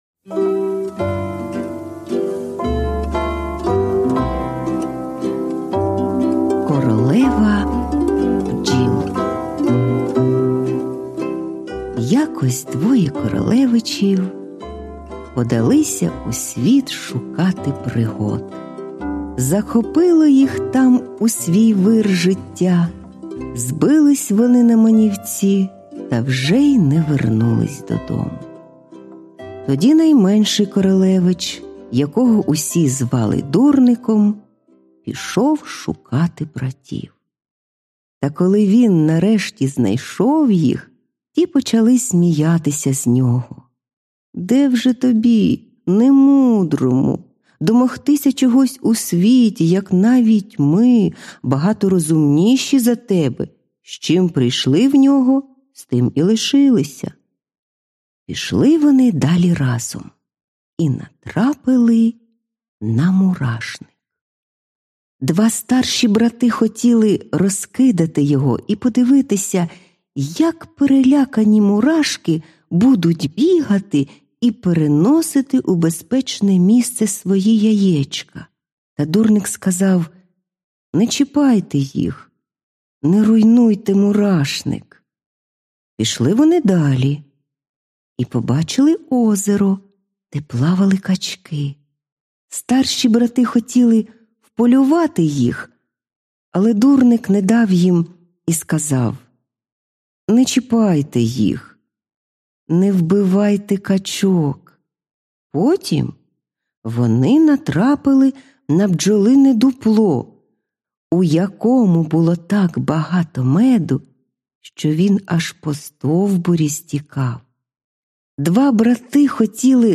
Аудіоказка Королева бджіл
Жанр: Літературна /Пригоди / Фантастика